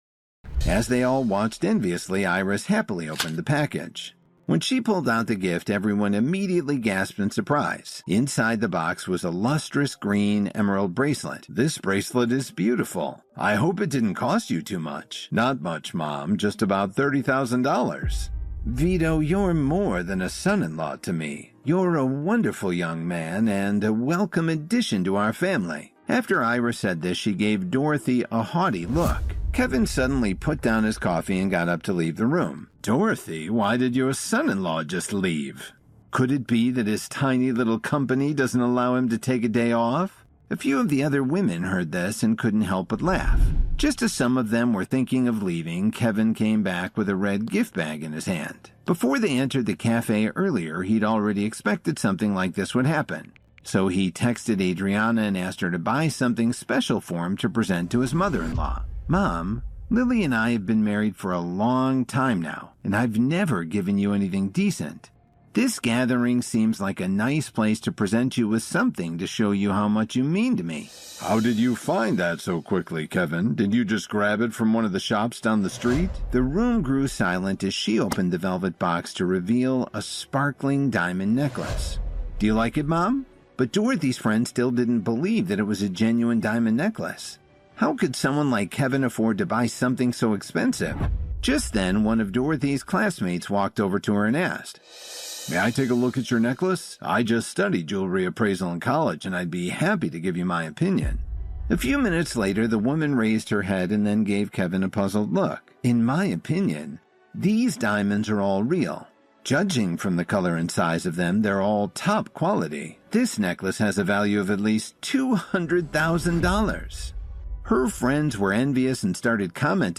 Fiction Mobile Series Sample